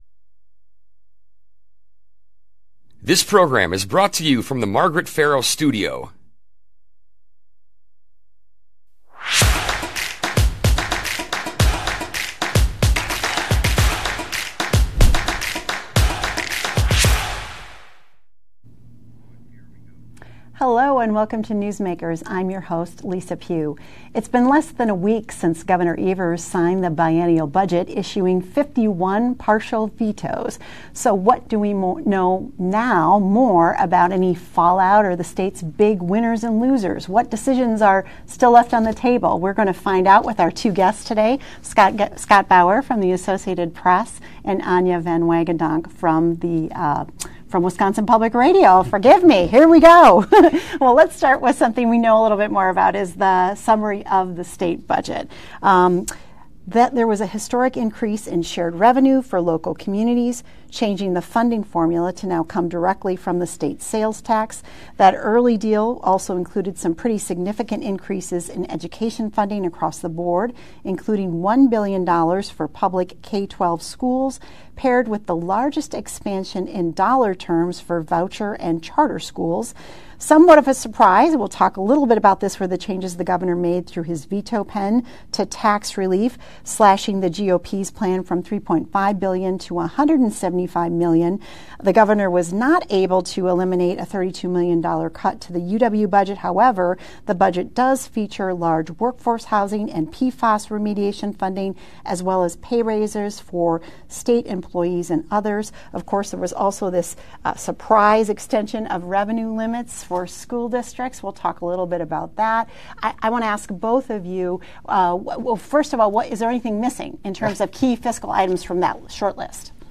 Newsmakers: Biennial Budget Recap Conversation with Capitol Reporters